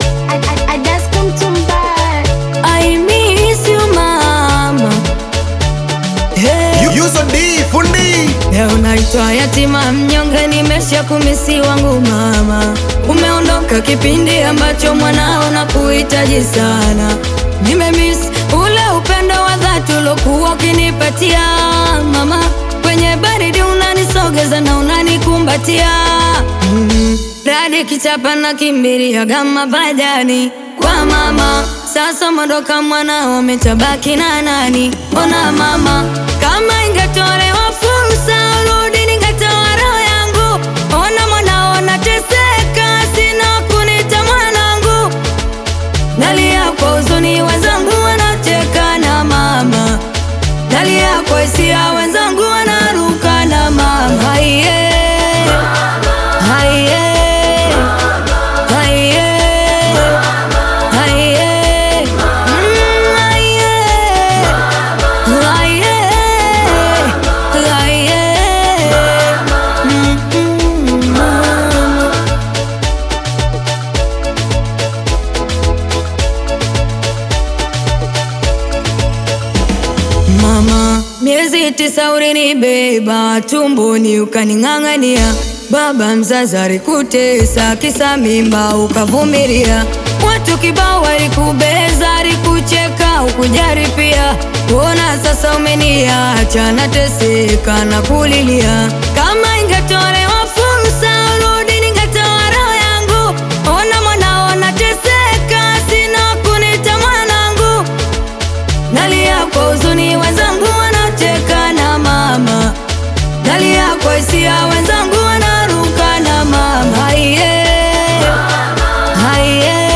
🎧 Genre: Singeli